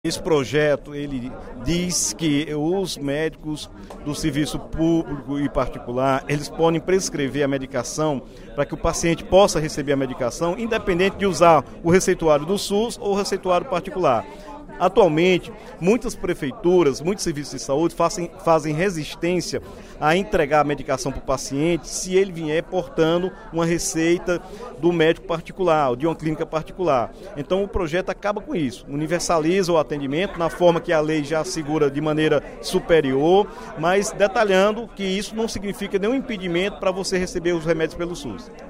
O deputado Dr. Santana (PT) destacou, nesta quinta-feira (24/09), durante o primeiro expediente da sessão plenária da Assembleia Legislativa, projeto de lei, de sua autoria, que prevê a gratuidade de medicamentos para pacientes que apresentarem receitas particulares nos postos de saúde. Segundo ele, muitas prefeituras só entregam os medicamentos se o paciente apresentar receita com o carimbo do Sistema Único de Saúde.